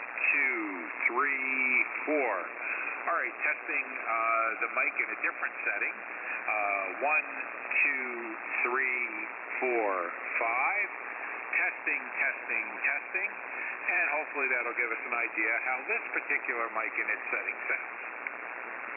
• Less bass
• Audio sound pinched
• All highs, no lows, very tinny
Heil HM10XD Narrow
Heil-HM10XD-Narrow.mp3